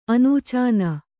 ♪ anūcāna